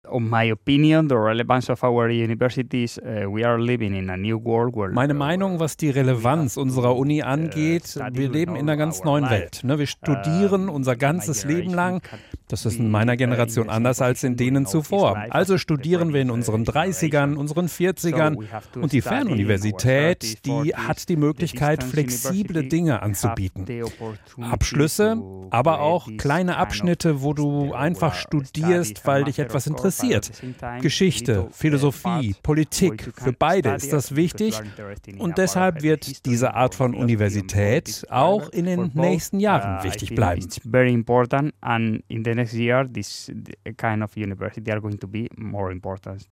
Anzeige Zukunft play_circle Abspielen download Anzeige MITSCHNITT AUS DER SENDUNG play_circle Abspielen